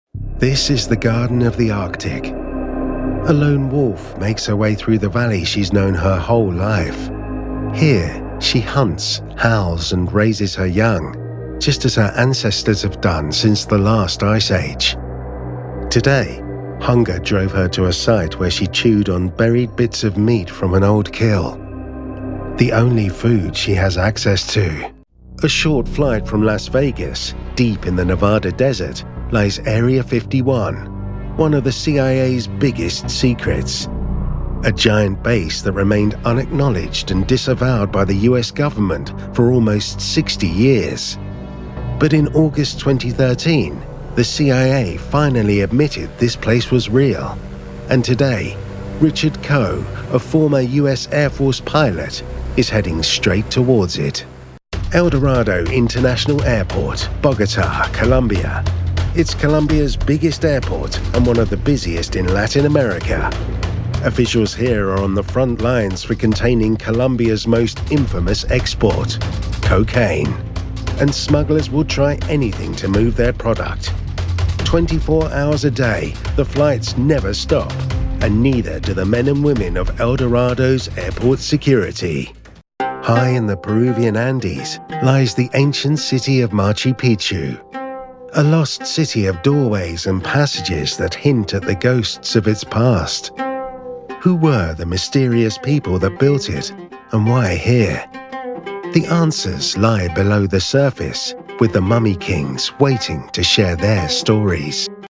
Englisch (Britisch)
Tief, Natürlich, Zugänglich, Freundlich, Warm
Unternehmensvideo